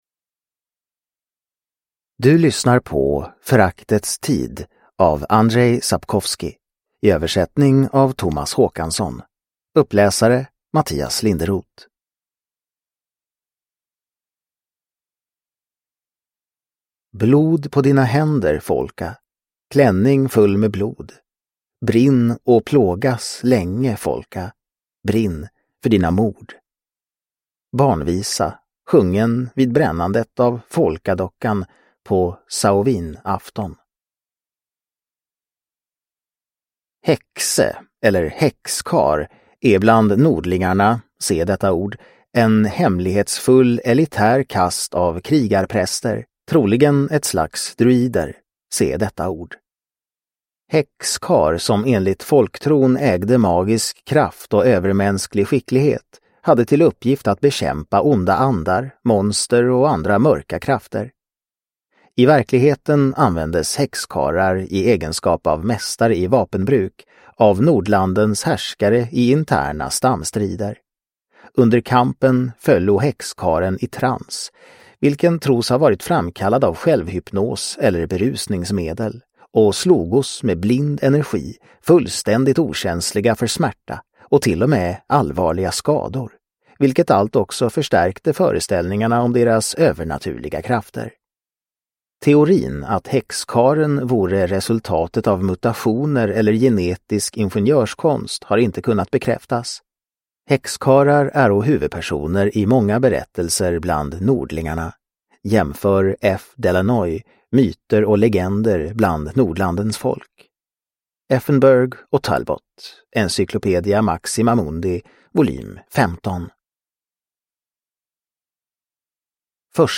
Föraktets tid – Ljudbok – Laddas ner